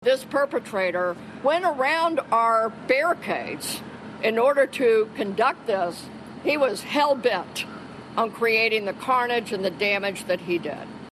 Superintendent Anne Kirkpatrick says it was an intentional act: